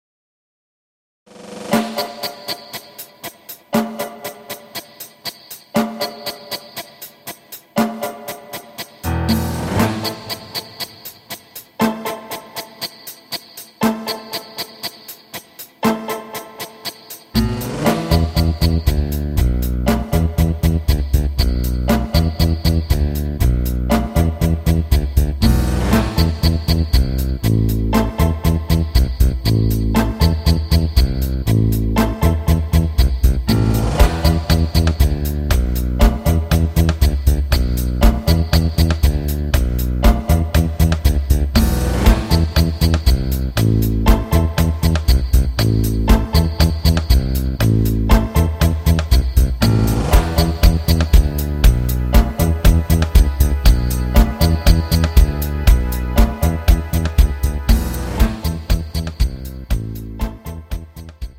Argentinischer Tango